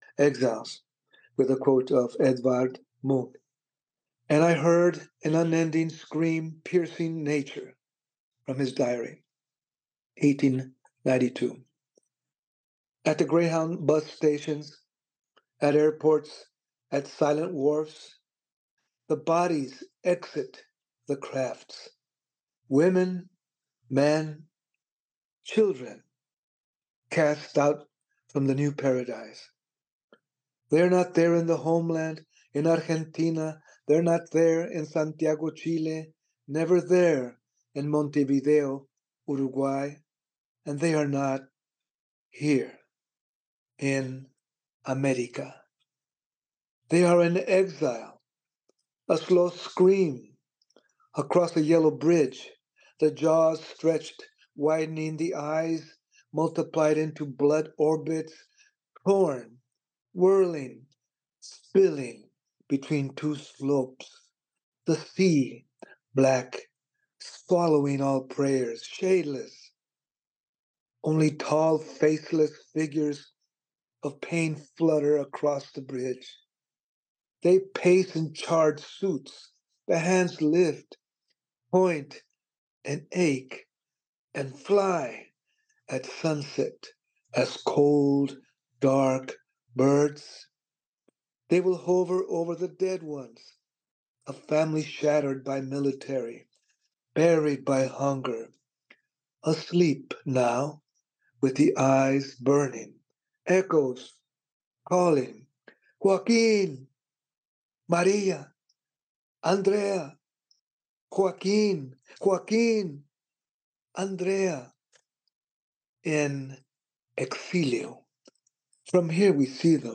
Juan Felipe Herrera, twenty-first Poet Laureate of the United States and a 2024 MacArthur “genius grant” recipient, sat down with Library of America to read and discuss poems from Latino Poetry: The Library of America Anthology.